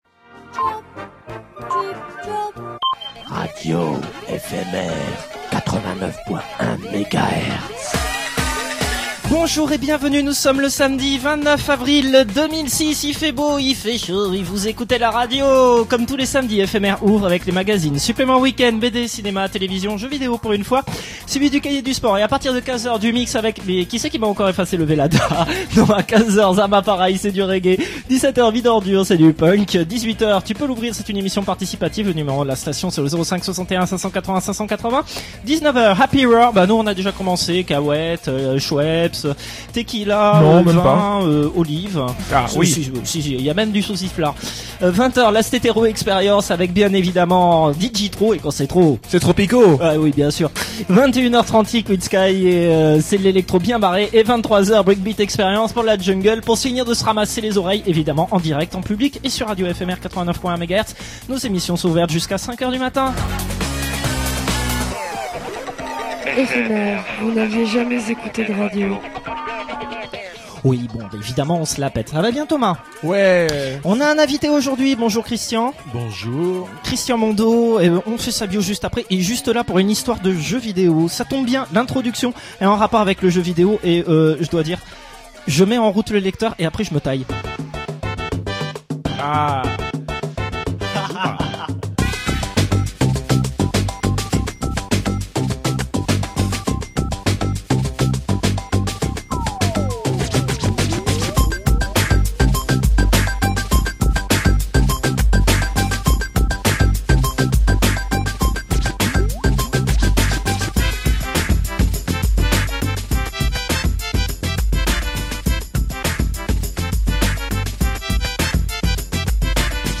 Spéciale Silent Hill avec en direct et en interview